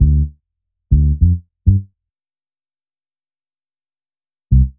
FK100BASS1-R.wav